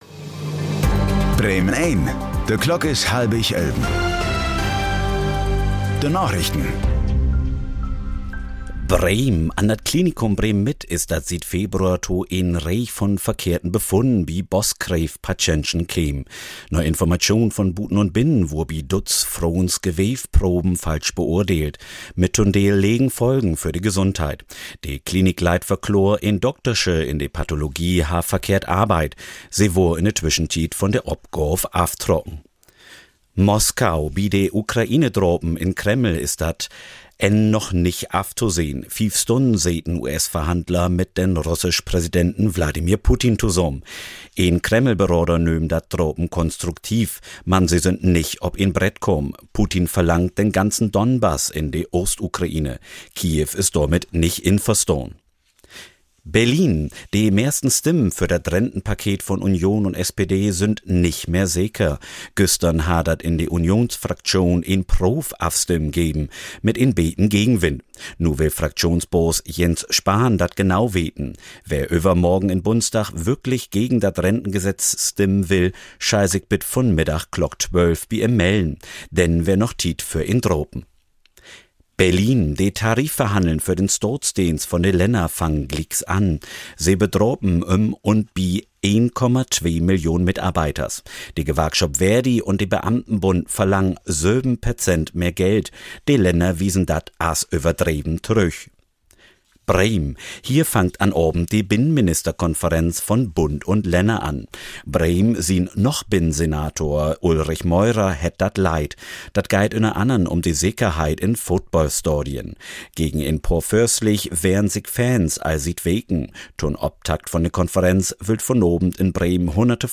Plattdüütsche Narichten vun'n 3. Dezember 2025